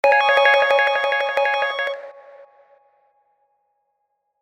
çoğunlukla eğlenceli ve hareketli zil seslerine sahip.